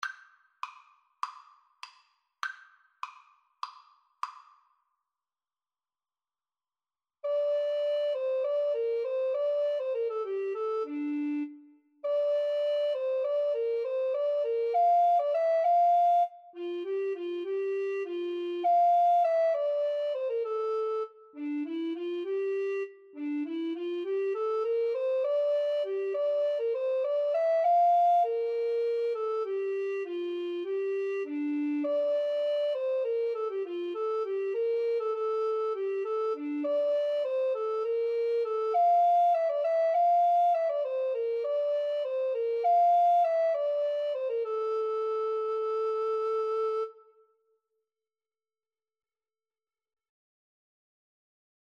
Classical (View more Classical Recorder Duet Music)